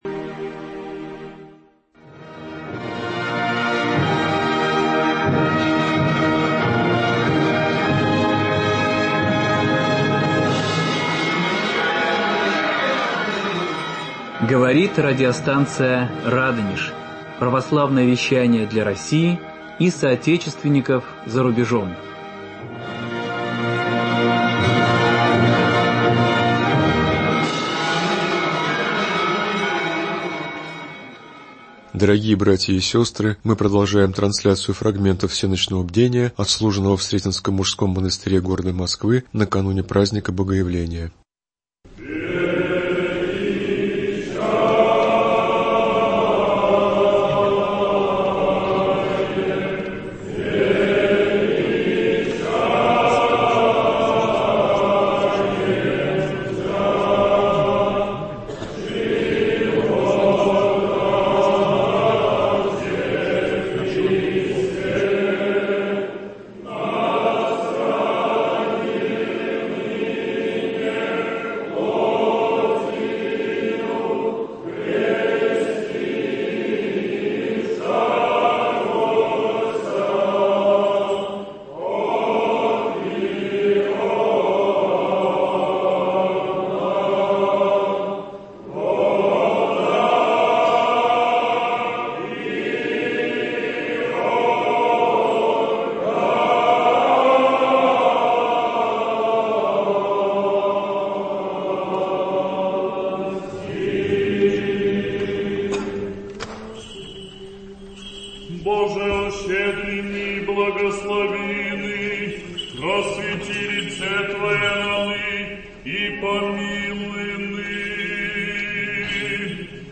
Фрагменты всенощного бдения, отслуженного под праздник Богоявления в Сретенском Московском мужском монастыре. Продолжение